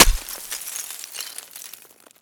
Add sound for clicking the card